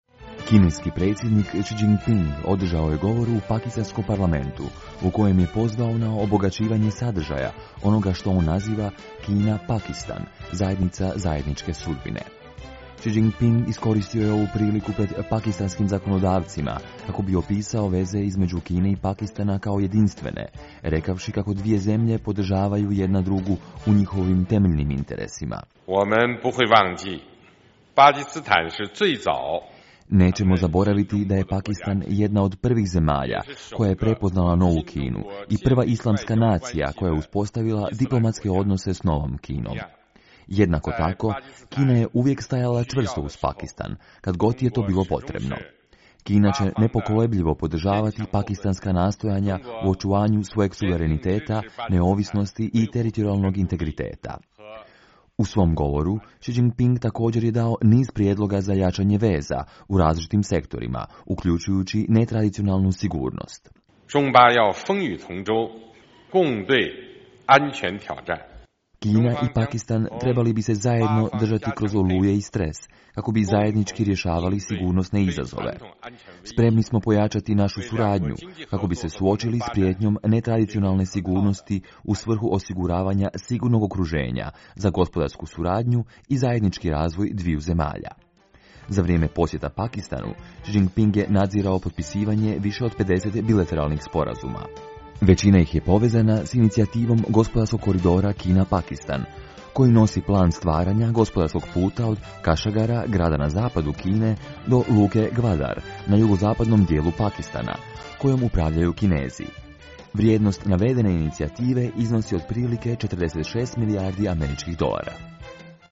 Kineski predsjednik Xi Jinping održao je govor u pakistanskom parlamentu u kojem je pozvao na obogaćivanje sadržaja onoga što on naziva Kina-Pakistan "zajednica zajedničke sudbine".